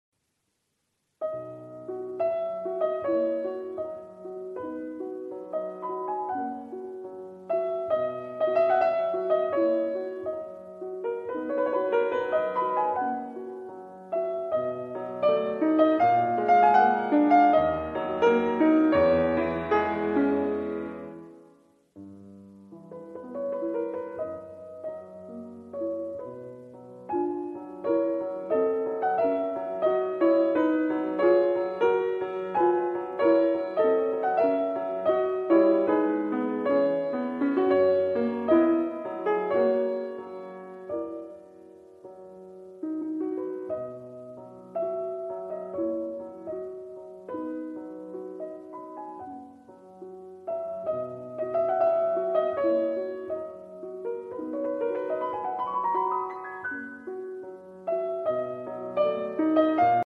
Fryderyk Chopin - Nocturne in B major Op.32 no 1